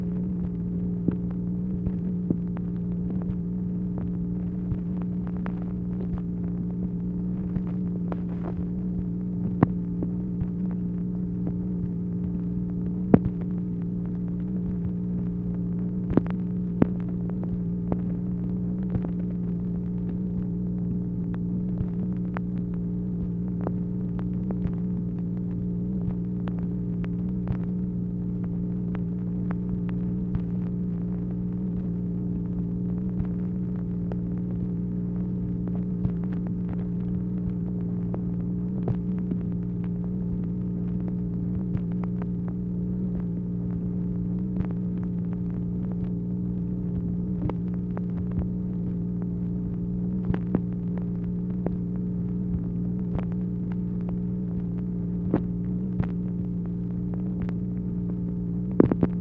MACHINE NOISE, February 3, 1964